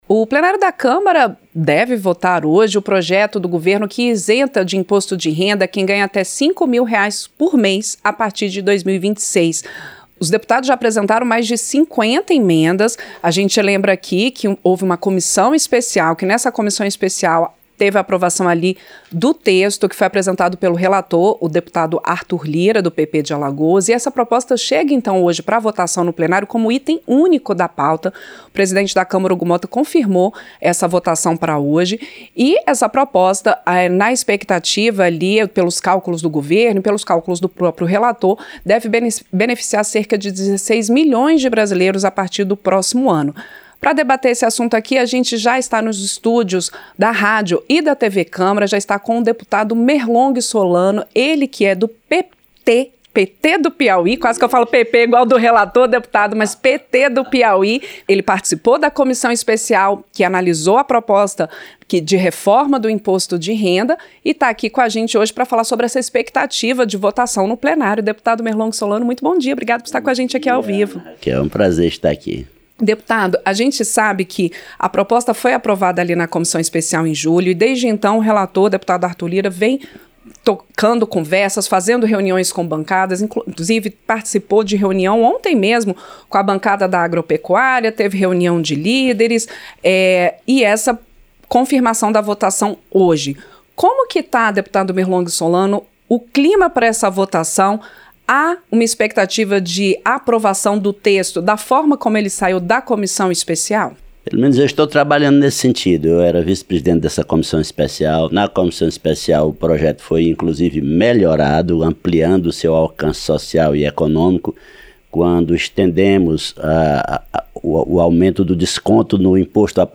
Entrevista - Dep. Merlong Solano (PT-PI)